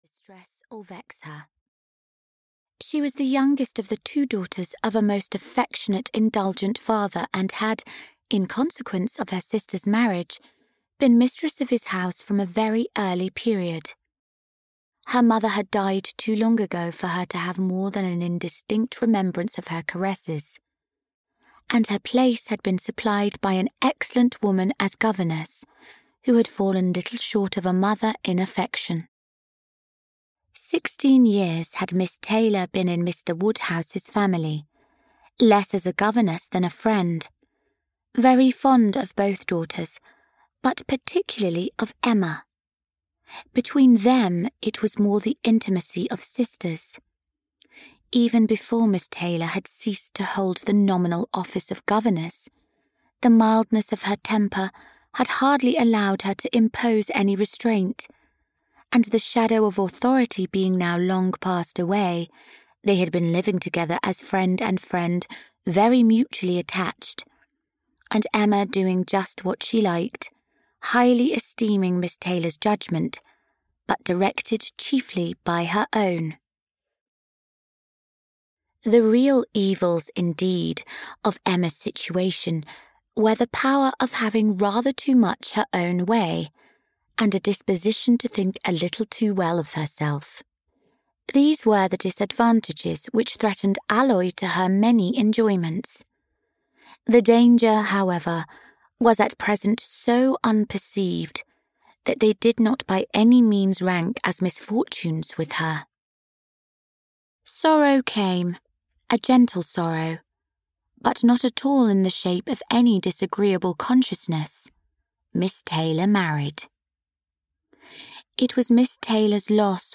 Audiobook Emma written by Jane Austen.
Ukázka z knihy
• InterpretKathryn Drysdale